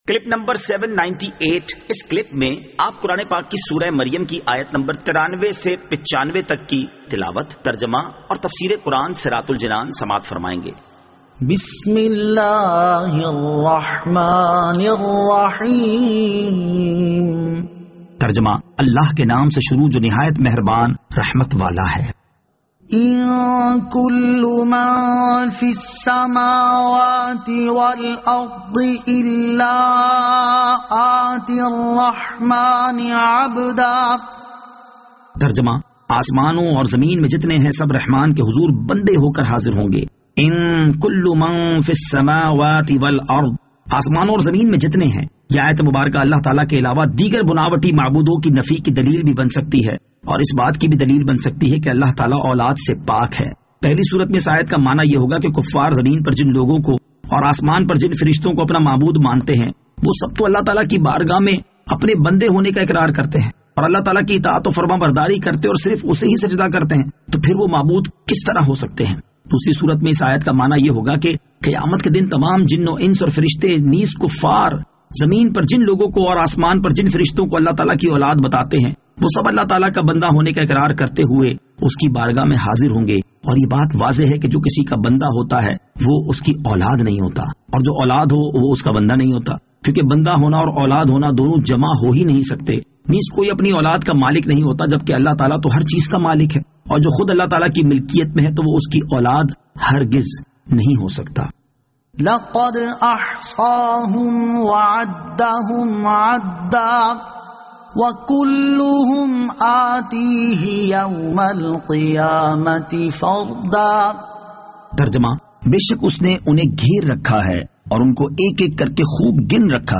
Surah Maryam Ayat 93 To 95 Tilawat , Tarjama , Tafseer